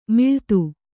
♪ miḷtu